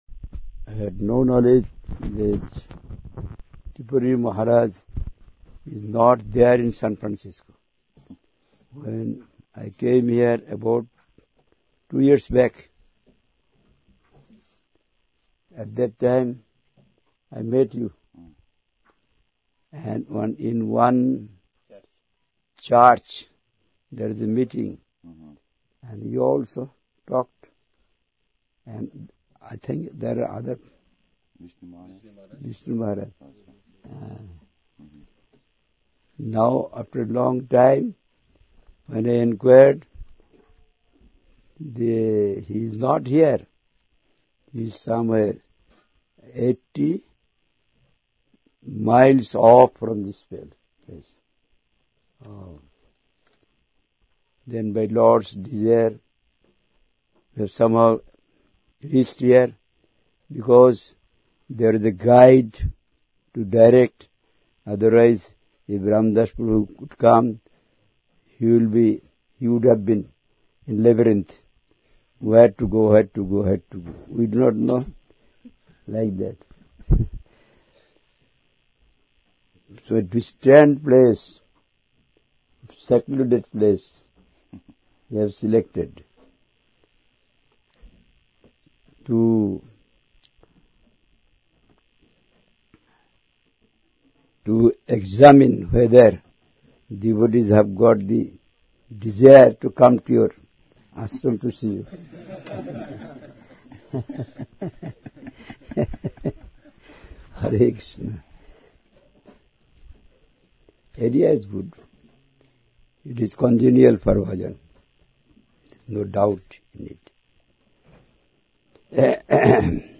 Highest form of worship - A talk